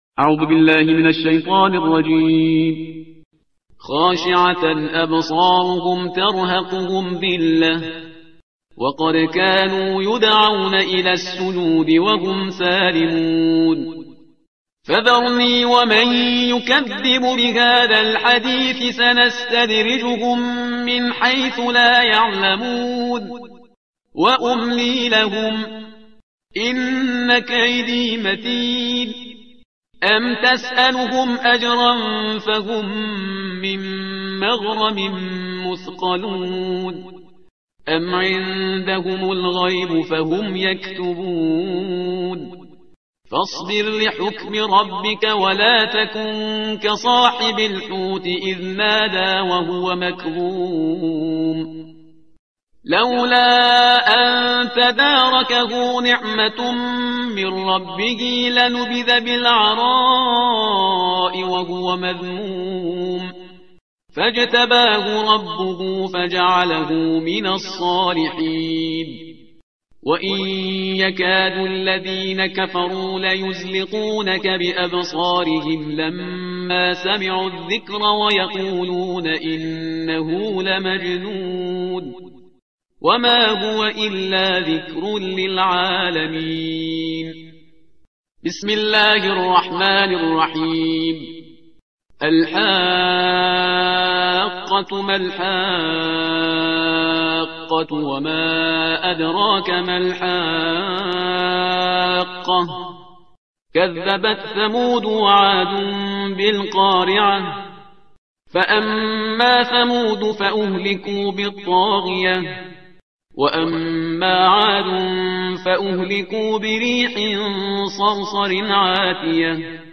ترتیل صفحه 566 قرآن با صدای شهریار پرهیزگار